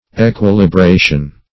Equilibration \E`qui*li*bra"tion\, n.